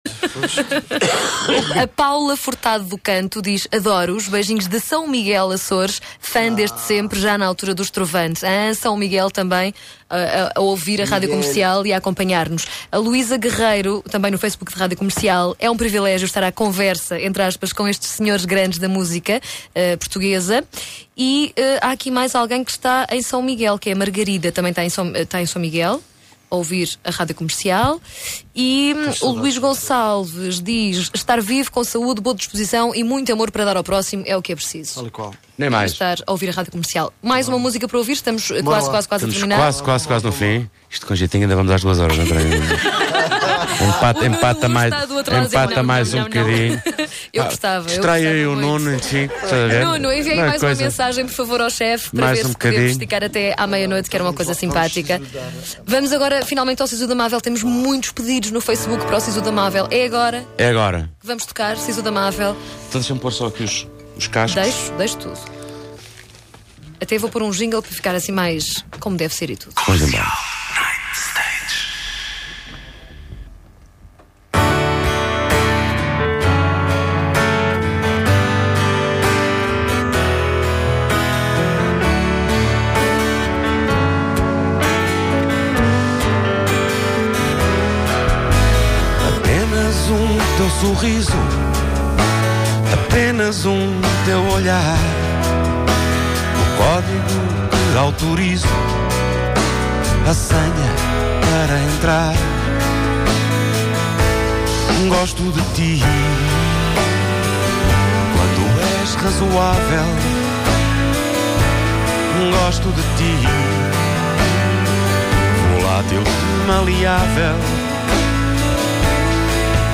Tertúlia com música à mistura